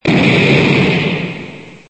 Descarga de Sonidos mp3 Gratis: explosion.